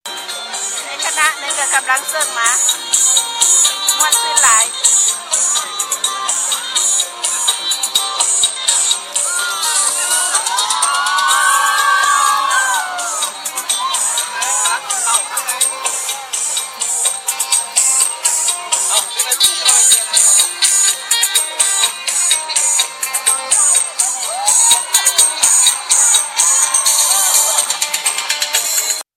ການເສບຟ້ອນເຊີ້ງບຸນຊ່ວງເຮືອ